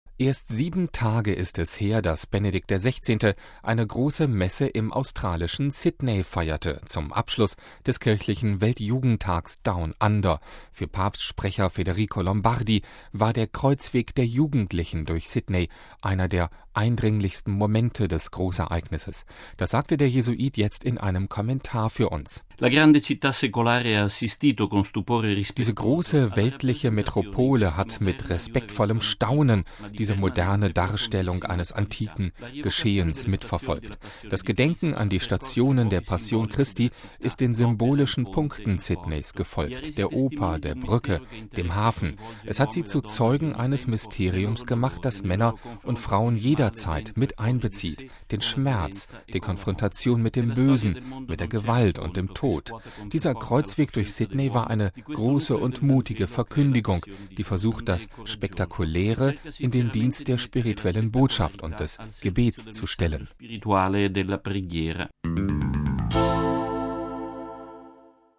Für Papst-Sprecher Federico Lombardi war der Kreuzweg der Jugendlichen durch Sydney einer der eindringlichsten Momente des Großereignisses. Das sagte der Jesuit jetzt in einem Kommentar für uns.